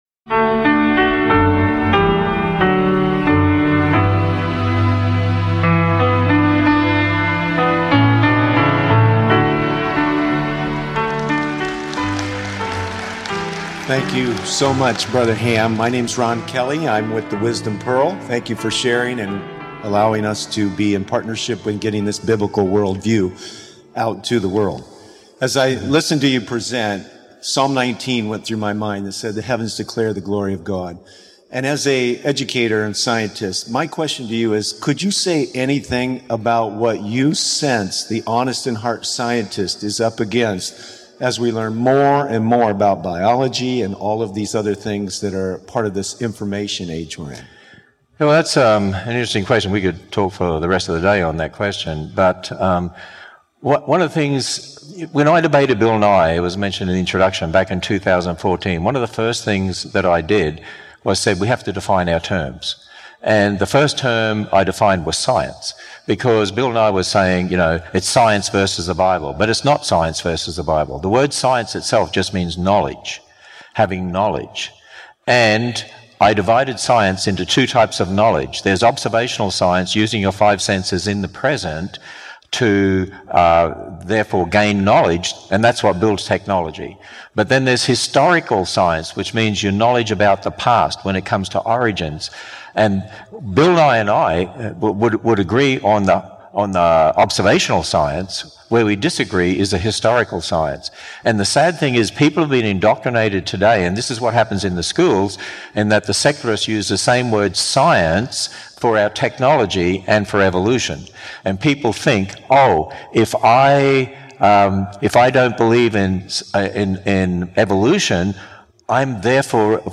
Q&A: AI & THE DAYS OF NOAH CONFERENCE 2024 (WITH KEN HAM) – American Christian Ministries